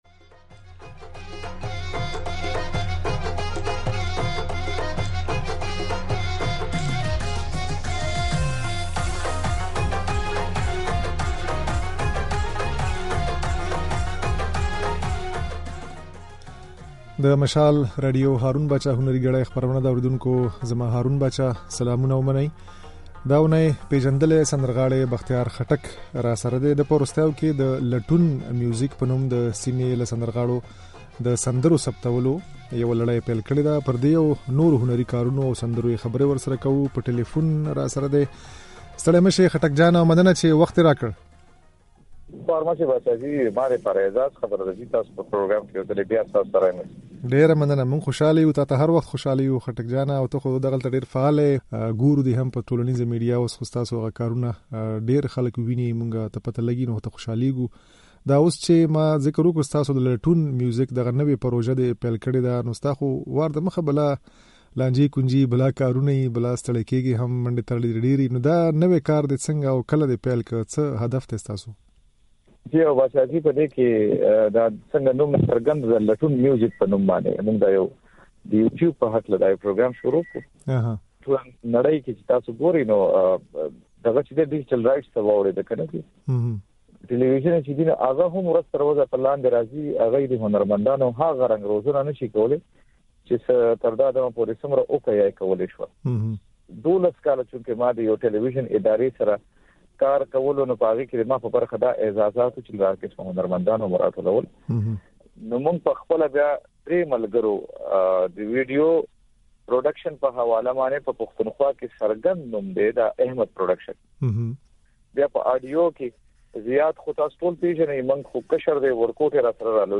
د دې اوونۍ په "هارون باچا هنري ګړۍ" خپرونه کې مو له پېژندلي سندرغاړي بختيار خټک سره د ده د "لټون ميوزک" پروژې په اړه خبرې کړې دي.
د خټک دا خبرې او دوی جوړې کړې ځينې سندرې د غږ په ځای کې اورېدای شئ.